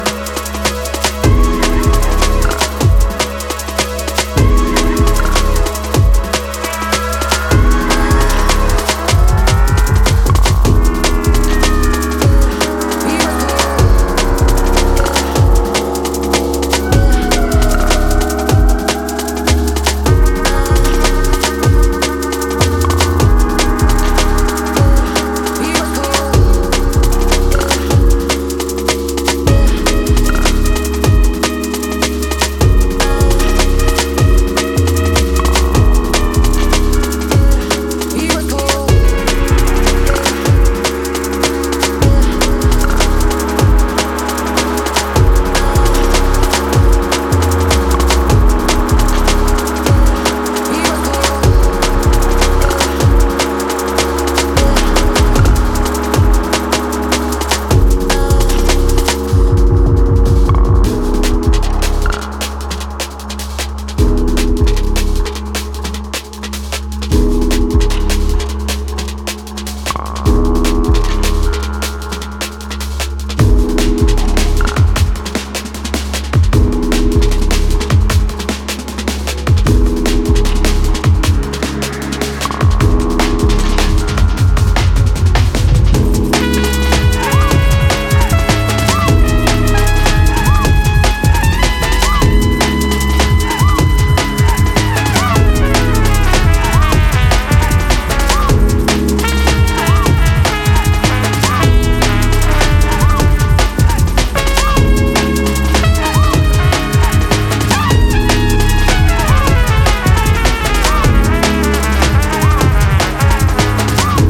five tracks across house, electro and breaks
liquid break swing and soft detail